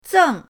zeng4.mp3